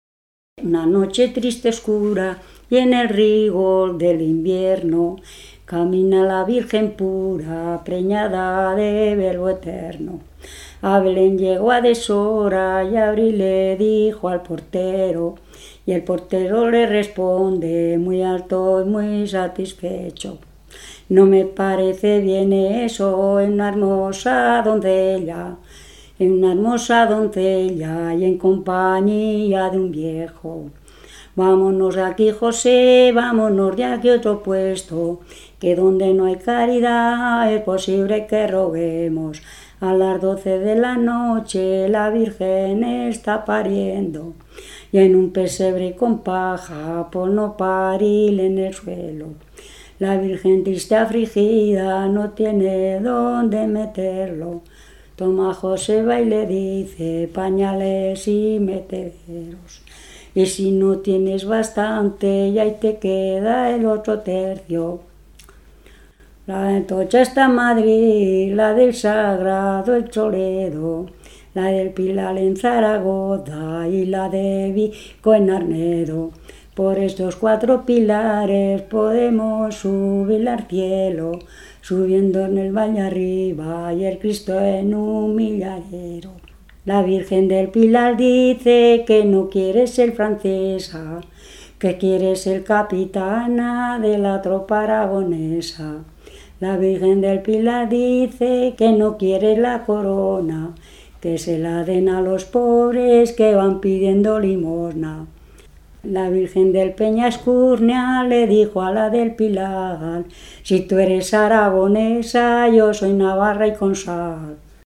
Clasificación: Romancero
Contexto: Intimidad familiar
Lugar y fecha de grabación: Arnedo, 29 de mayo de 1999